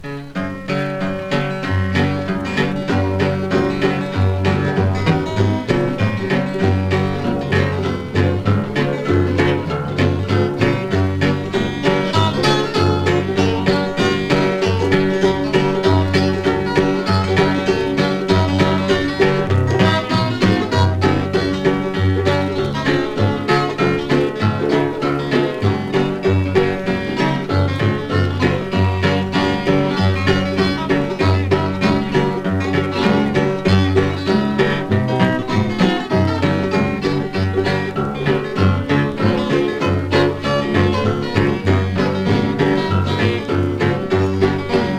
歌唱、楽曲、楽器とすべてがハワイ満点、魅惑的な良盤です。
World, Hawaii　USA　12inchレコード　33rpm　Mono